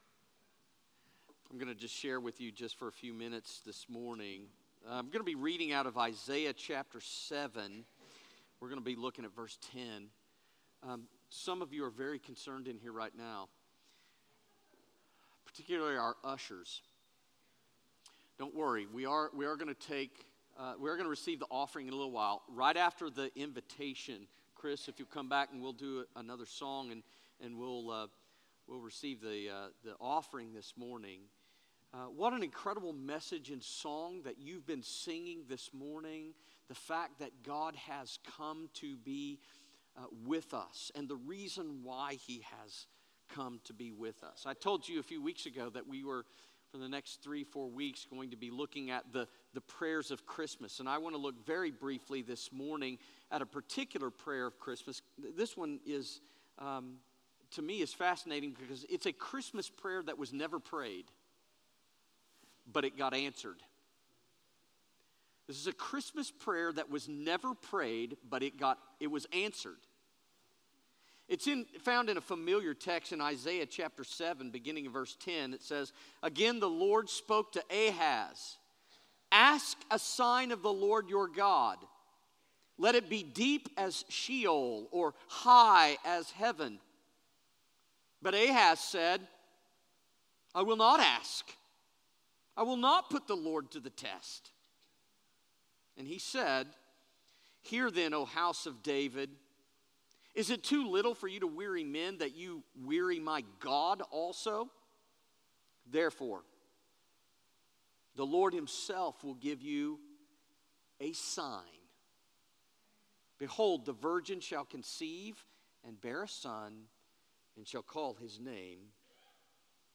Unto Us - Worship Presentation